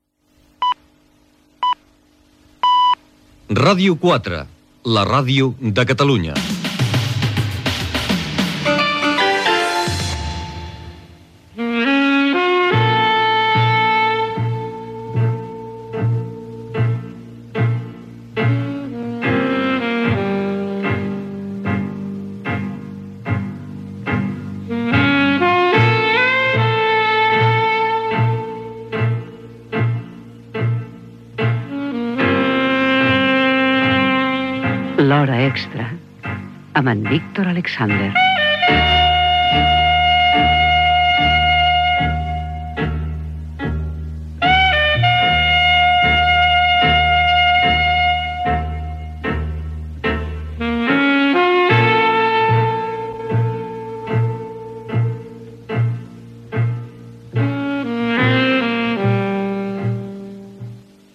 Senyals horaris, indicatiu i sintonia del programa.
FM